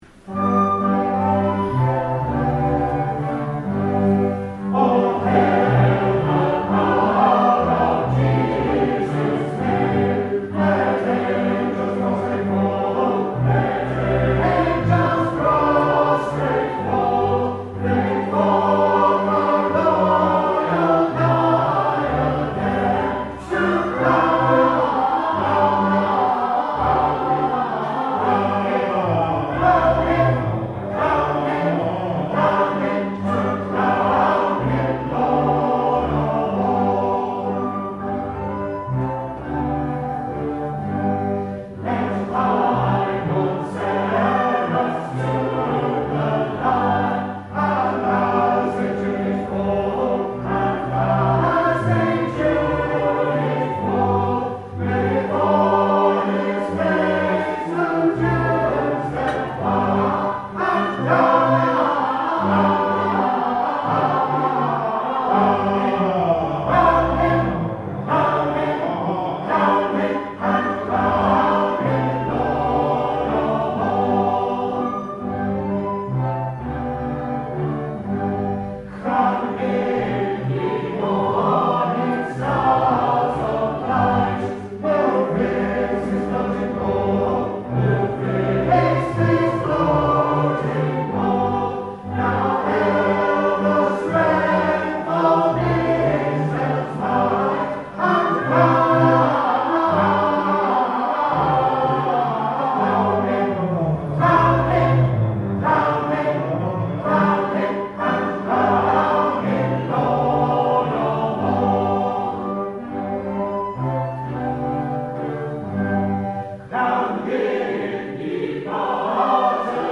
This wonderfully bouncy rendition, including all eight verses, was sung at a West Gallery music day on 4 October 2025 by With Cheerful Voice, an ad hoc choir. West Gallery music is not accompanied on the organ or piano, so we had a band of musicians.